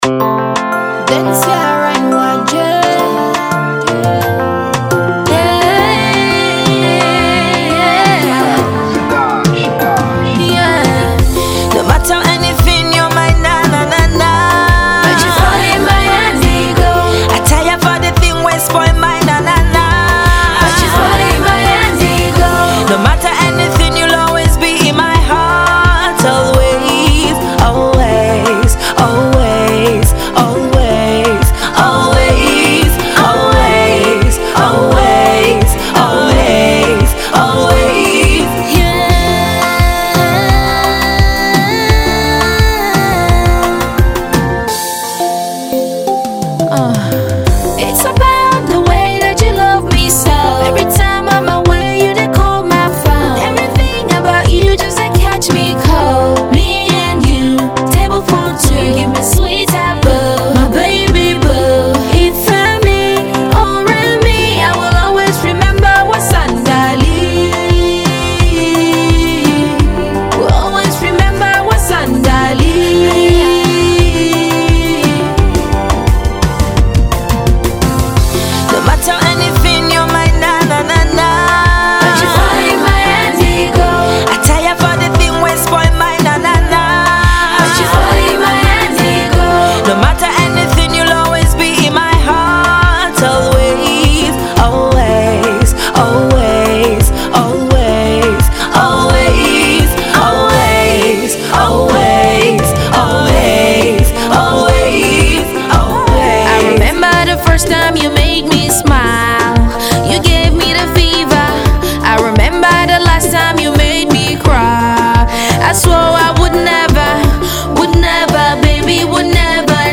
sweet melodic Zouk song